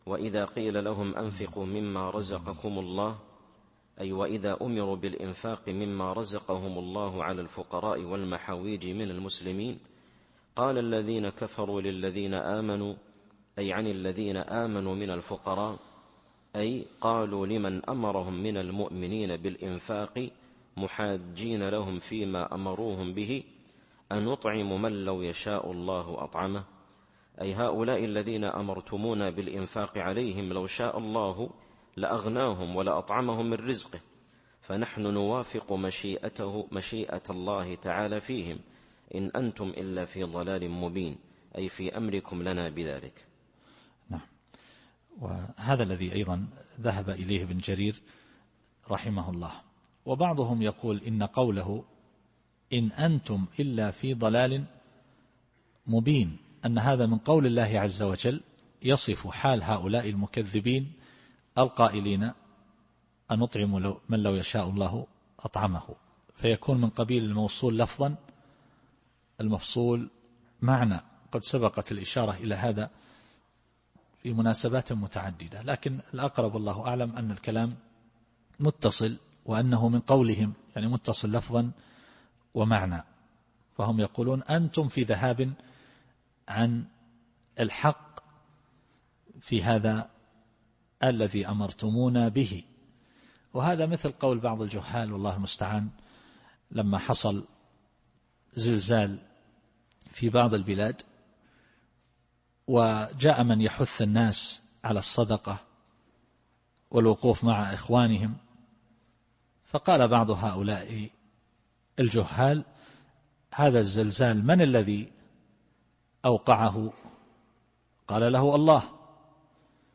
التفسير الصوتي [يس / 47]